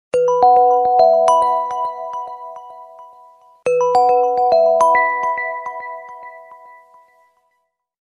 Pleasant sound sms ringtone free download
Message Tones